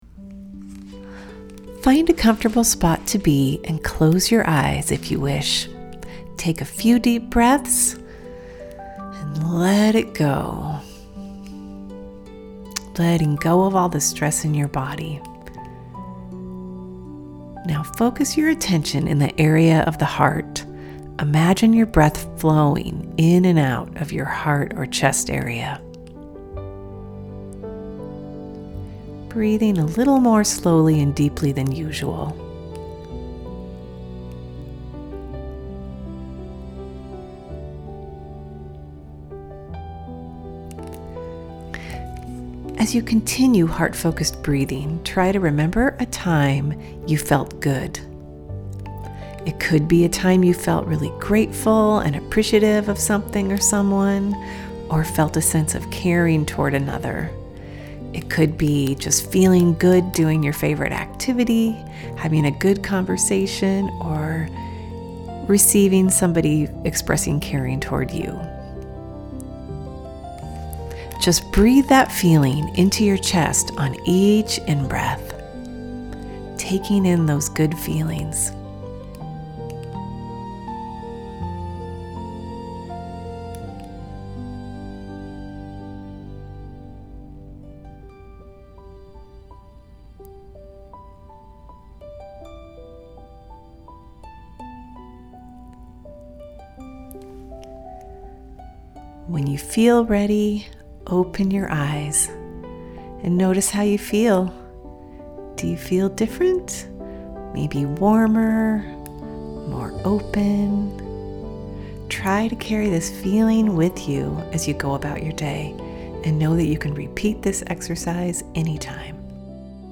This quick and easy meditation from the HeartMath Institute puts you into a state of heart-brain coherence, which helps you feel better, think more clearly, and be more intuitive.